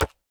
resin_brick_hit3.ogg